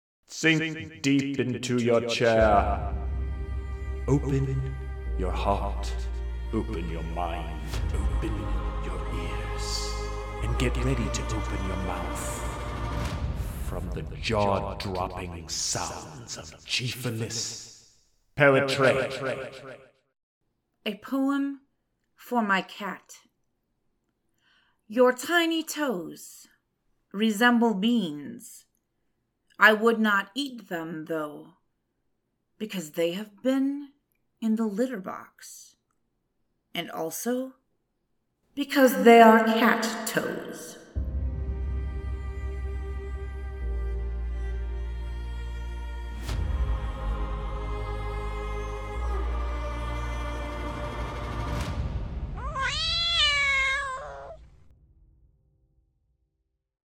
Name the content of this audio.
Genre: POETRY